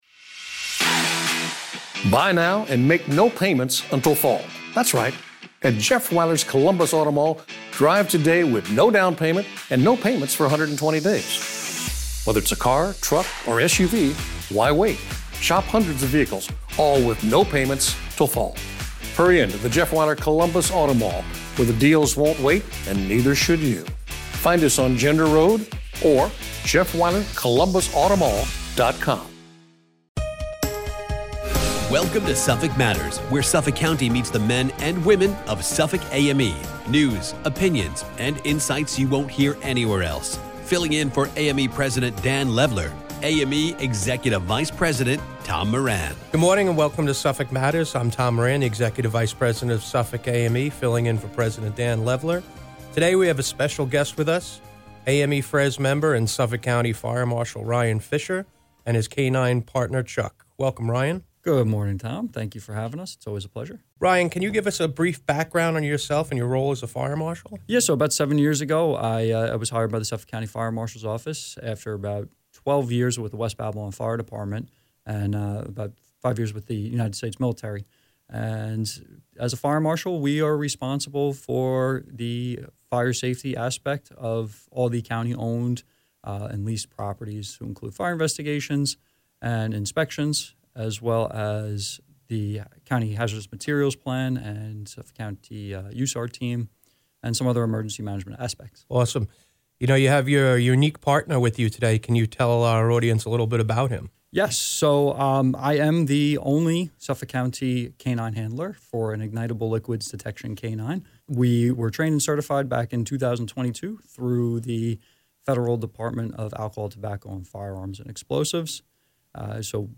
speaks with Suffolk County Fire Marshal Ryan Fischer.